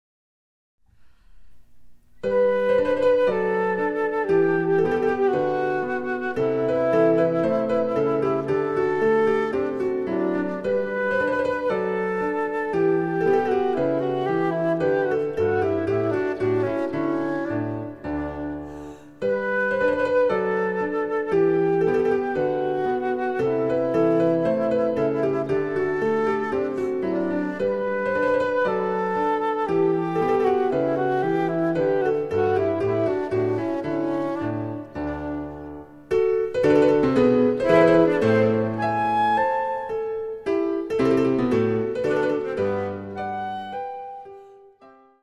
伴奏つきでフルートが演奏できる！
デジタルサンプリング音源使用